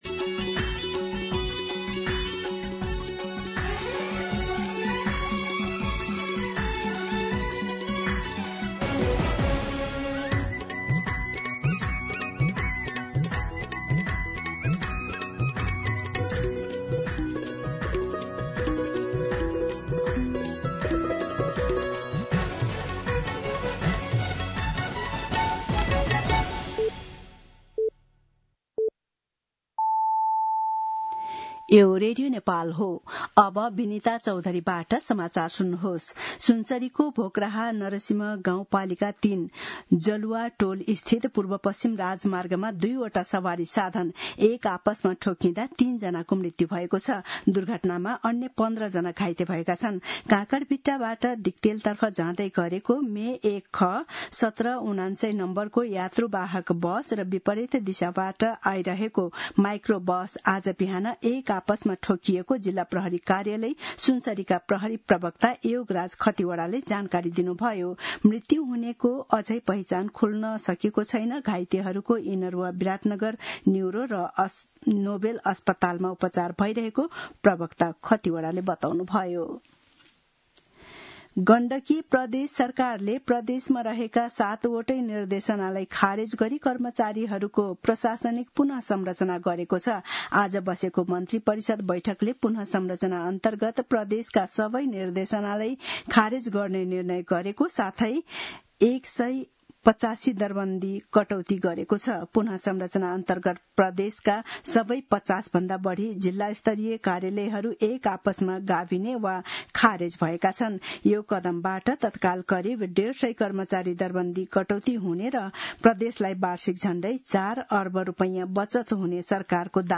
दिउँसो १ बजेको नेपाली समाचार : २६ साउन , २०८२
1-pm-Nepali-News-4.mp3